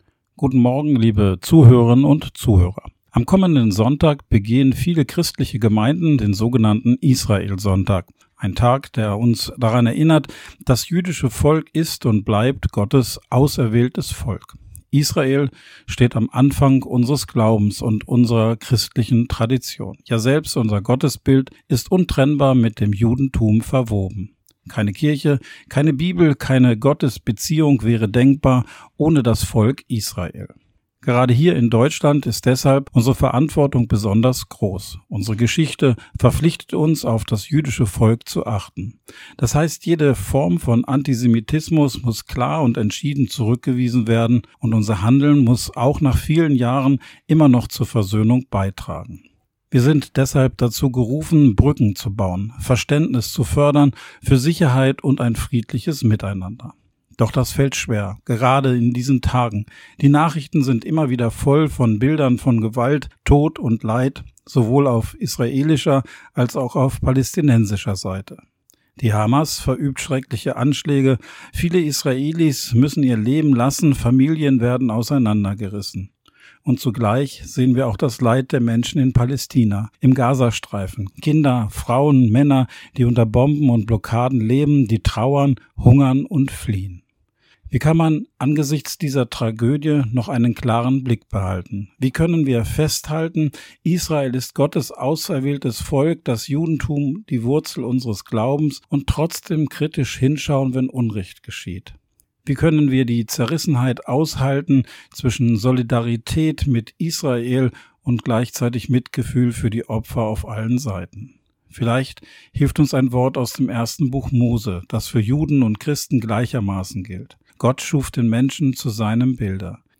Radioandacht vom 21.August